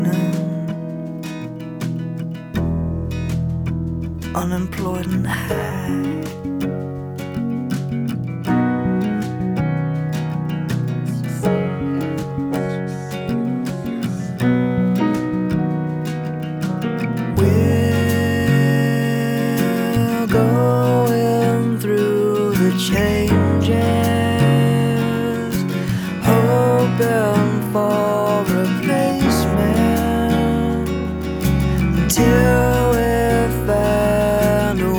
Alternative Rock Adult Alternative Pop Singer Songwriter
Жанр: Поп музыка / Рок / Альтернатива